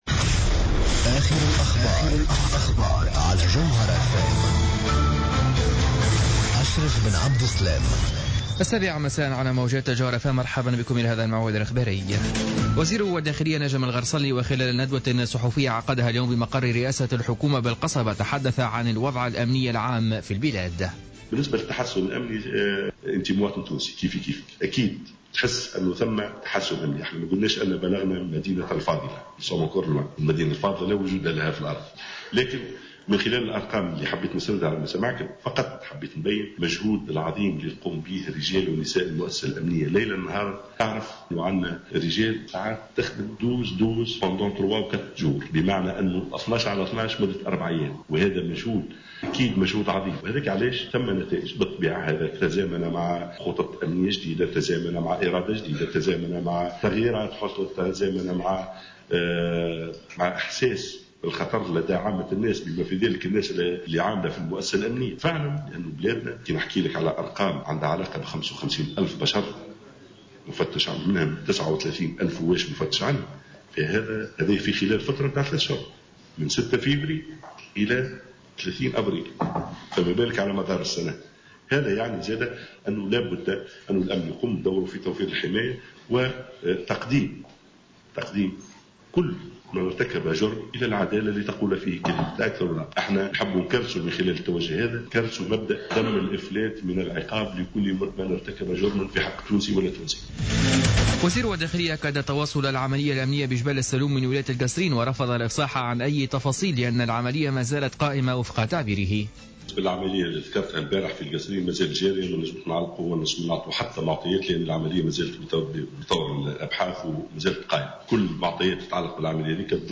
نشرة أخبار السابعة مساء ليوم الثلاثاء 05 ماي 2015